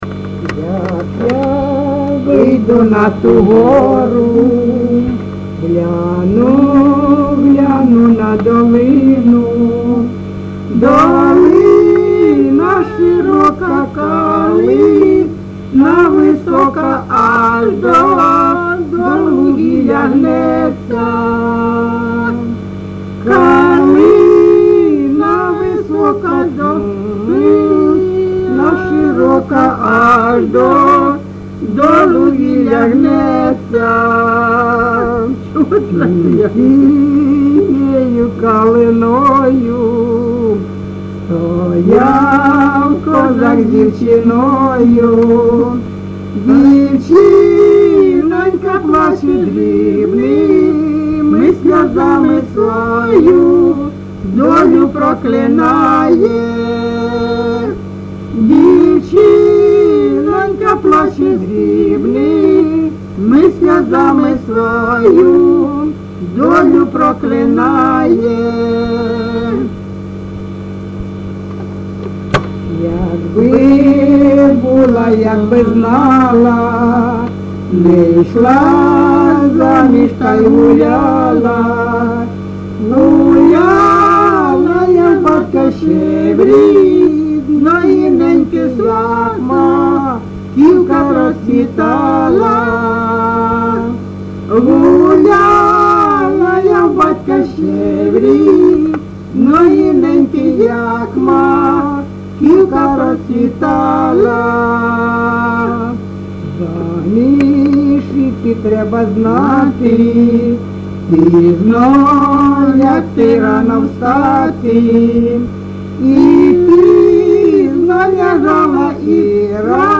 ЖанрПісні з особистого та родинного життя
Місце записус. Калинове Костянтинівський (Краматорський) район, Донецька обл., Україна, Слобожанщина